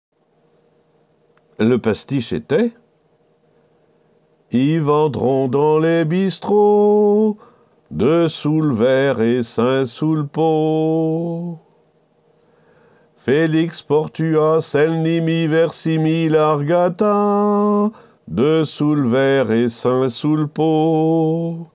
Mais à l’arrière, d’autres paroles se chantonnaient sur les mêmes airs.